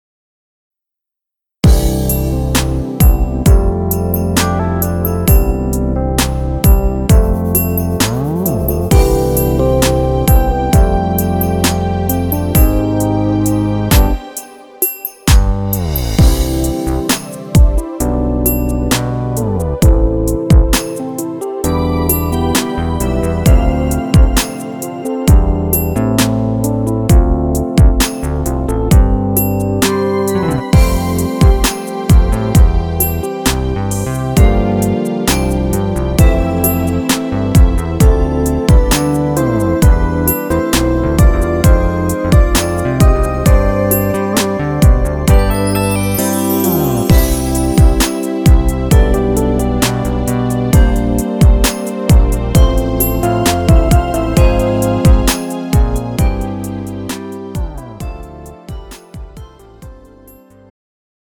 음정 남자키
장르 축가 구분 Pro MR